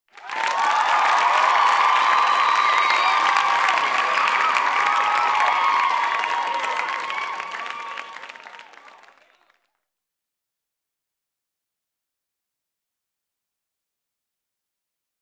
Sound Effects - Crowd Applause with Cheering